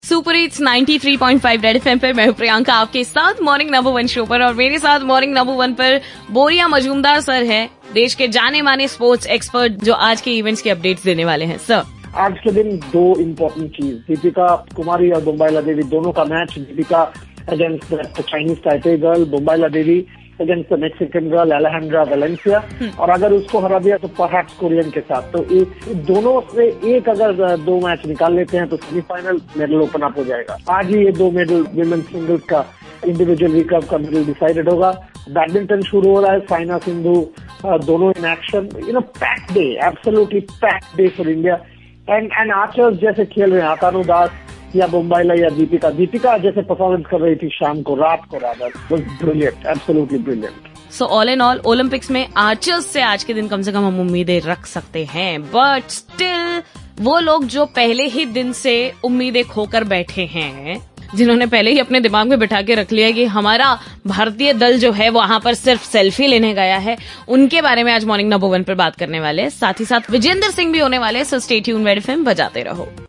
talkng with Boria Majumdar direct from rio olympics